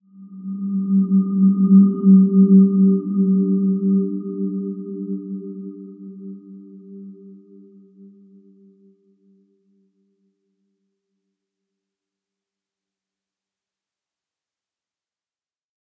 Dreamy-Fifths-G3-p.wav